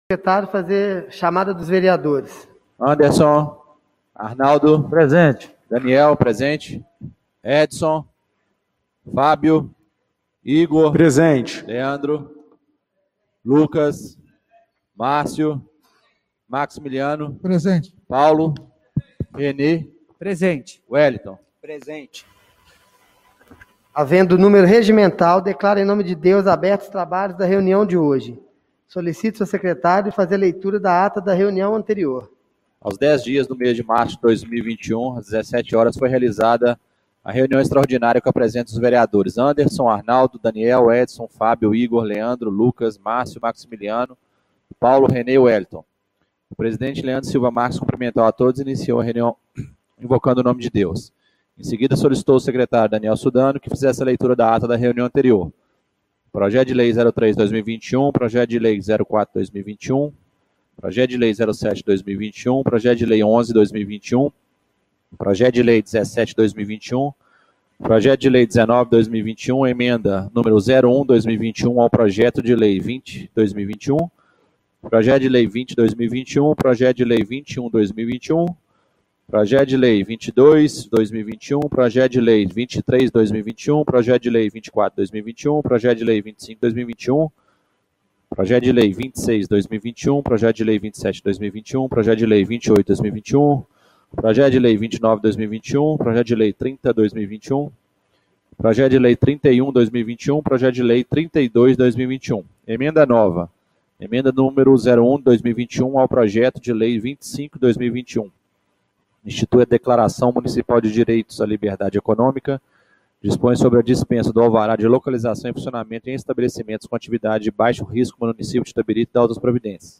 Reunião Extraordinária do dia 11/03/2021